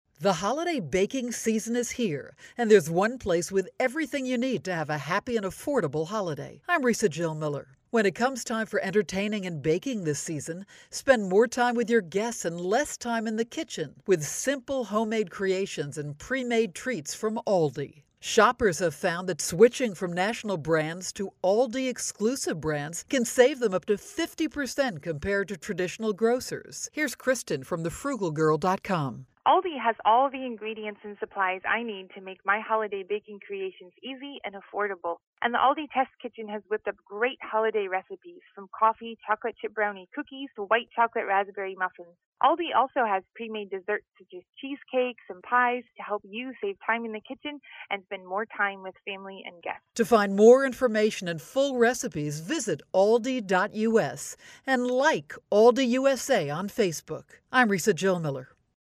December 16, 2013Posted in: Audio News Release